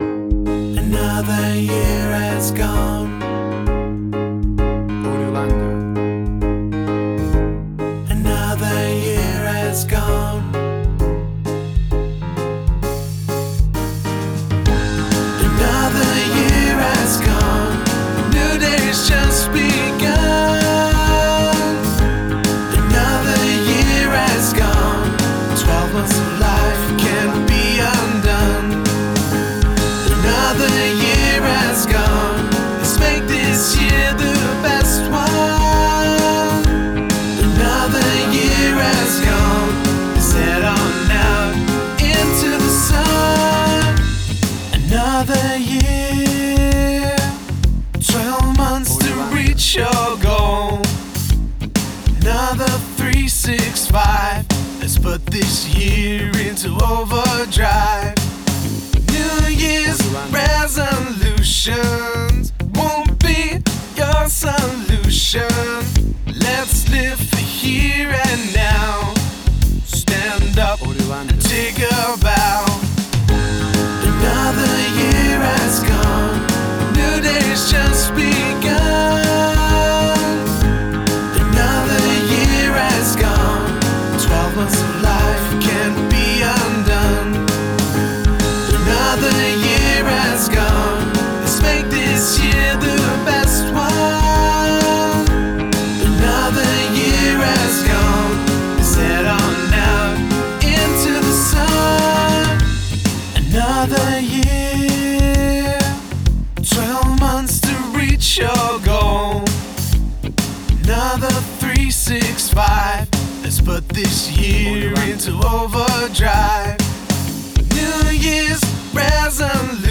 A cool vocal pop song all about new years and new years eve!
Upbeat and Uptempo vocal music with full vocal productio
Tempo (BPM): 132